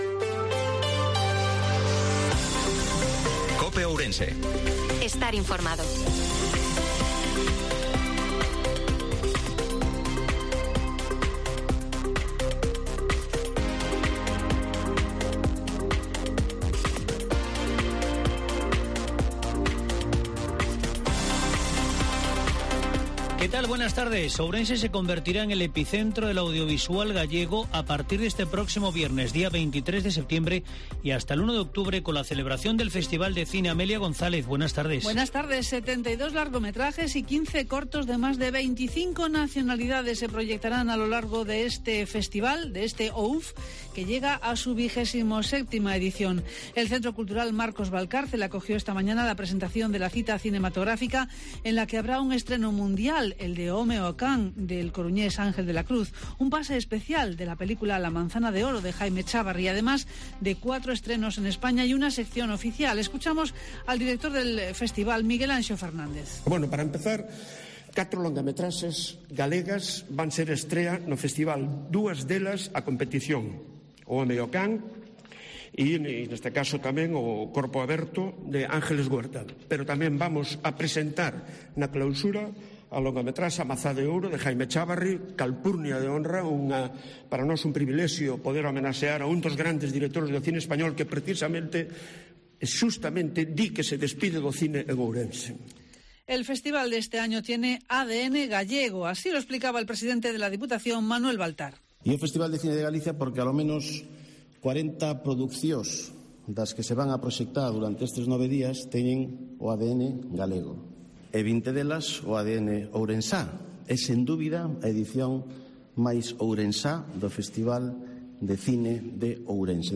INFORMATIVO MEDIODIA COPE OURENSE-20/09/2022